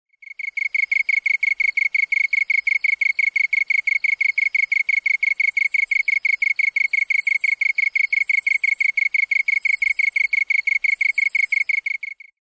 カンタン　Oecanthus longicaudaコオロギ科
日光市稲荷川中流　alt=730m  HiFi --------------
0'38'' Windows Media Audio FILE MPEG Audio Layer3 FILE  Rec.: SONY TC-D5M
Mic.: audio-technica AT822
他の自然音：　 ヤマヤブキリ